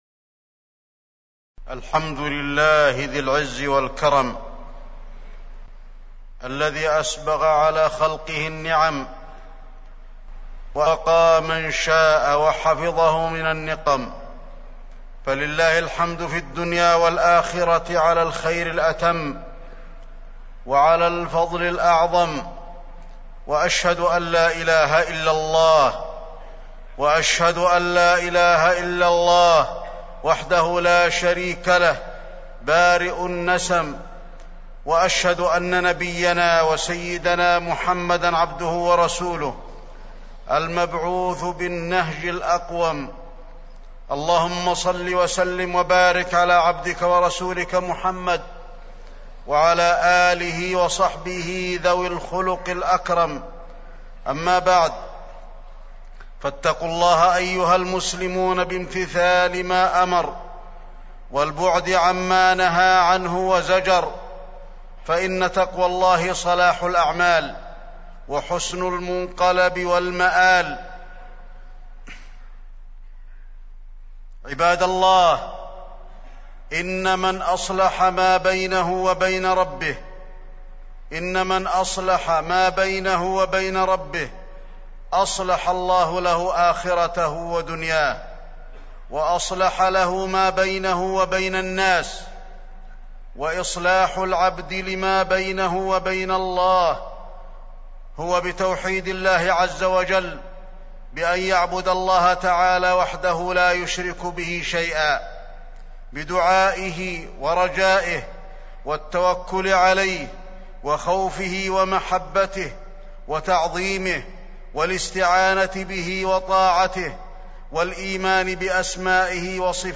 تاريخ النشر ٢١ رجب ١٤٢٦ هـ المكان: المسجد النبوي الشيخ: فضيلة الشيخ د. علي بن عبدالرحمن الحذيفي فضيلة الشيخ د. علي بن عبدالرحمن الحذيفي الخوف والرجاء